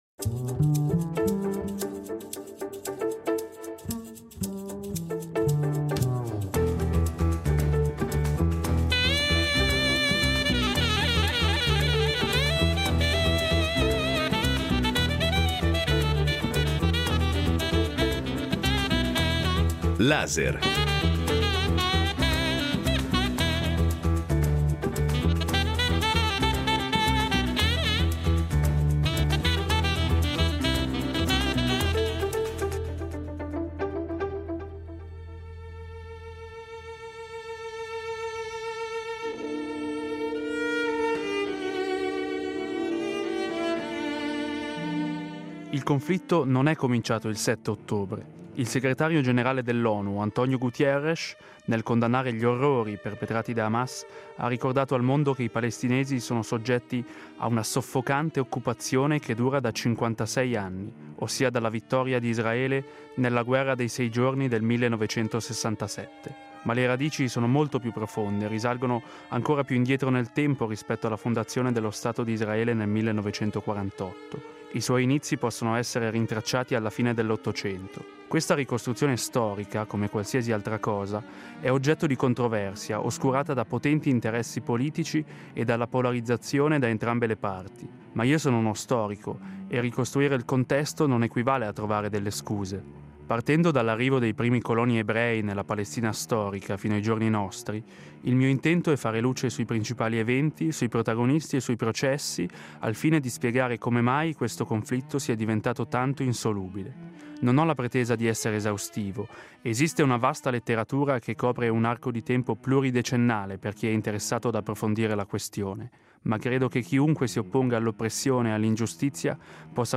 Colloquio con lo storico israeliano Ilan Pappé (1./2)